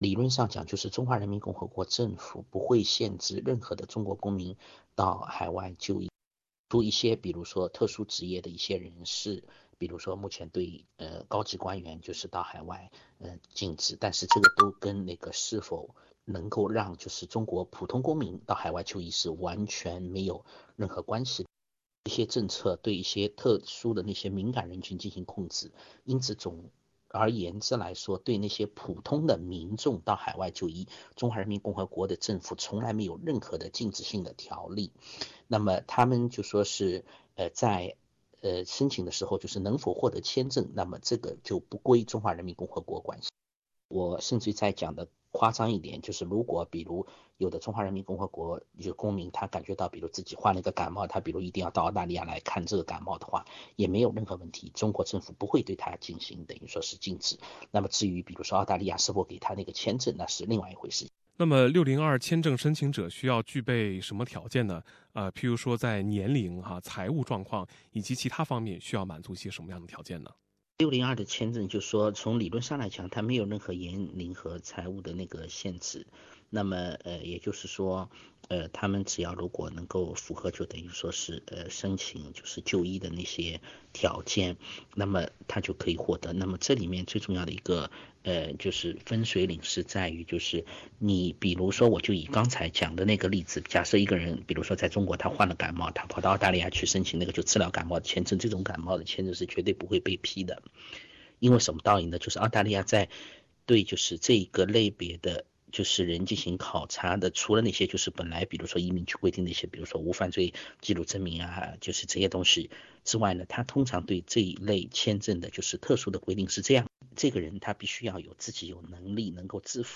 海外患者来澳就医需要知道的那些事儿 07:55 by SBS SBS 普通话电台 View Podcast Series Follow and Subscribe Apple Podcasts YouTube Spotify Download (3.63MB) Download the SBS Audio app Available on iOS and Android 2017年是澳中旅游年。